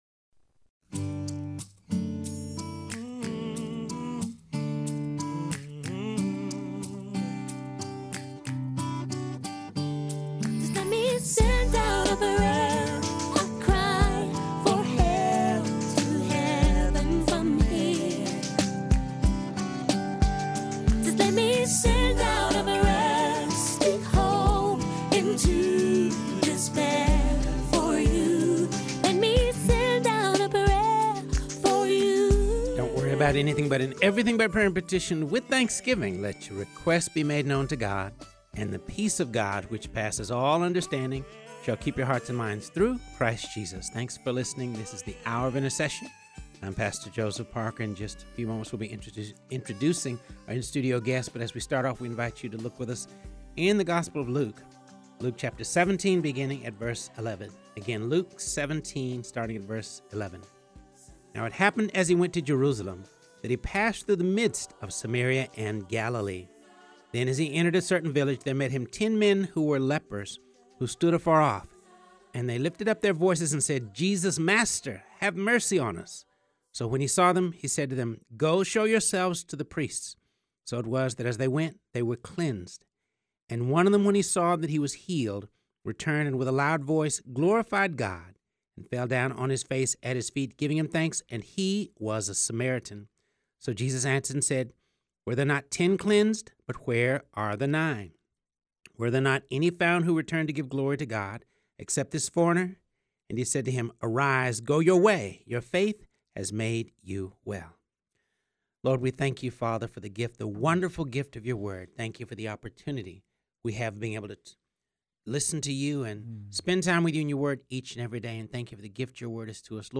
joined in studio for the first half of the show